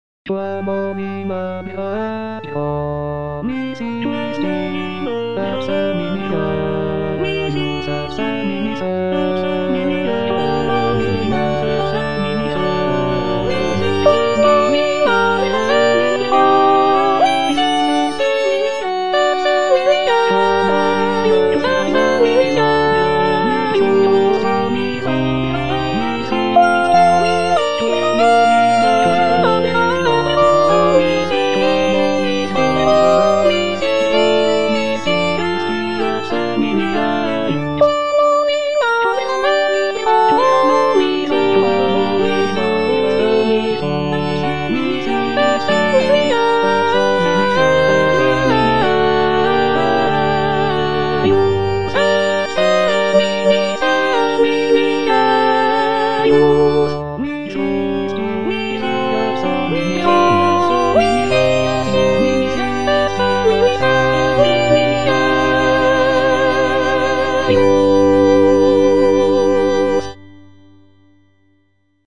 M. HAYDN - REQUIEM IN C (MISSA PRO DEFUNCTO ARCHIEPISCOPO SIGISMUNDO) MH155 Quam olim Abrahae - Soprano (Emphasised voice and other voices) Ads stop: auto-stop